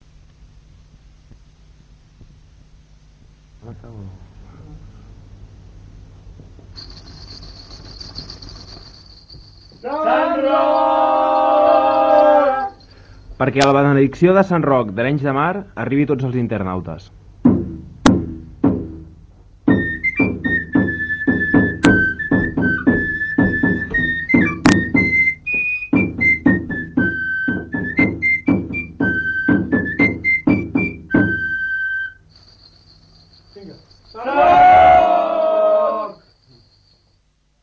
Benedicció dels macips pels internautes
La festa de Sant Roc i els macips, a Arenys de Mar